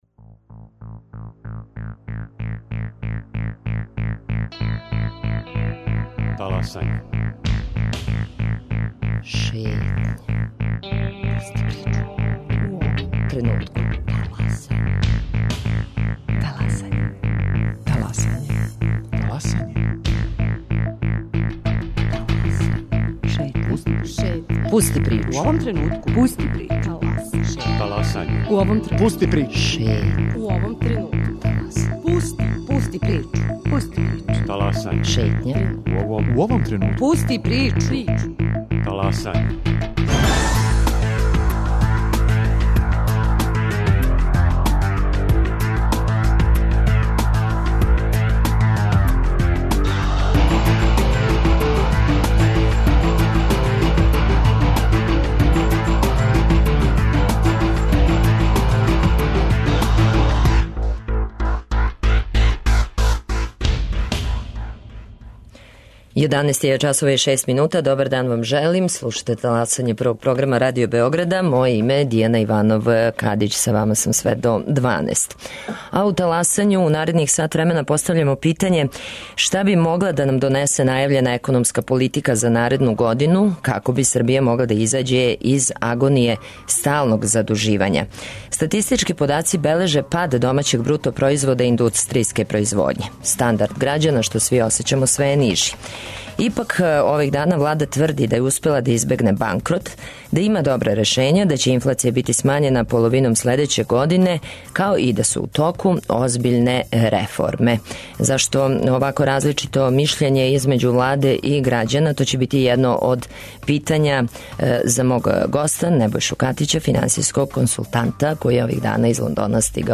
Tko podnosi ekavicu